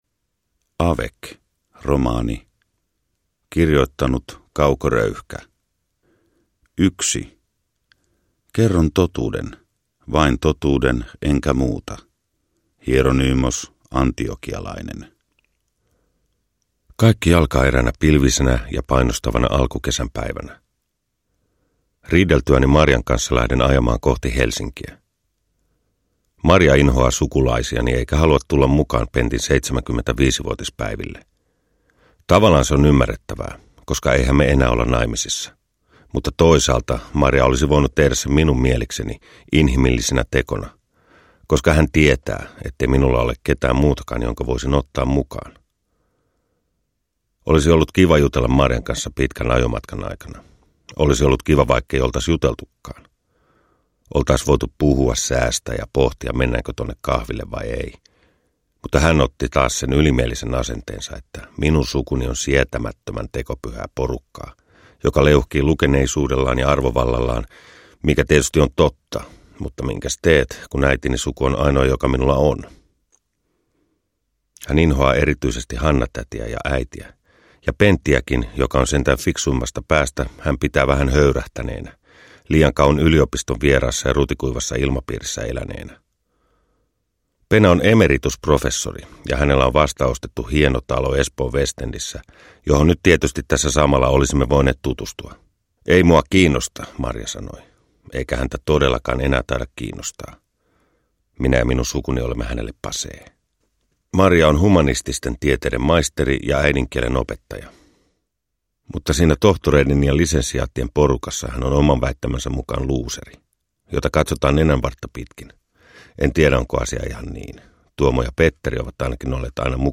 Avec – Ljudbok – Laddas ner
Hypnoottinen tajunnanvirtaromaani Antti Reinin lukemana!